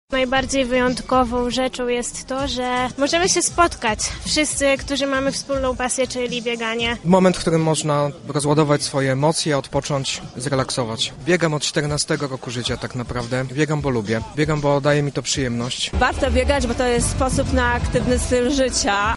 -podkreślają sami uczestnicy.
sonda-dycha.mp3